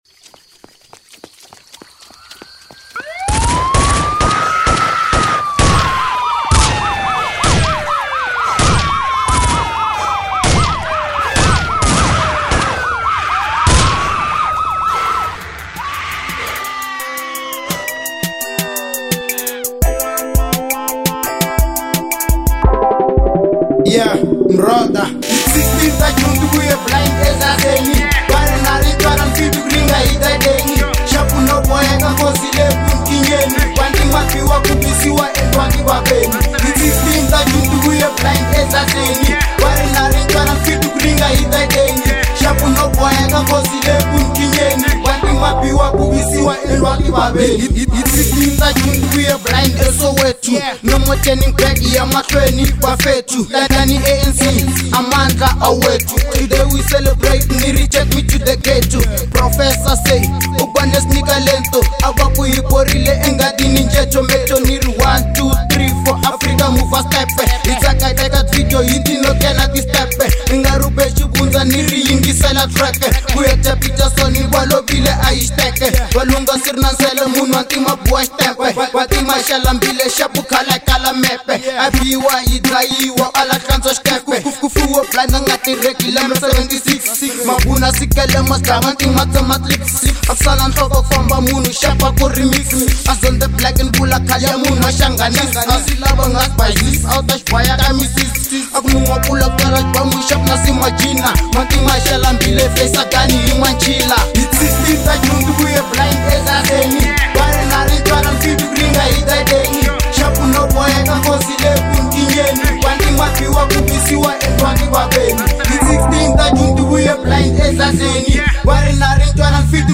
04:56 Genre : Hip Hop Size